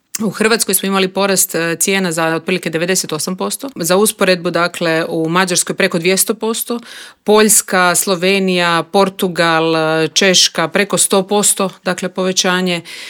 Upravo o toj temi razgovarali smo u Intervjuu Media servisa s HDZ-ovom europarlamentarkom Nikolinom Brnjac.